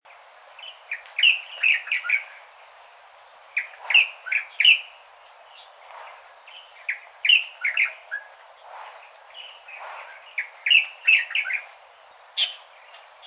白頭翁(台灣亞種) Pycnonotus sinensis formosae
高雄市 鼓山區 柴山
錄音環境 雜木林
雄鳥鳴唱
Sennheiser 型號 ME 67